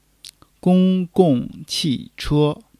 gong1-gong4-qi4-che1.mp3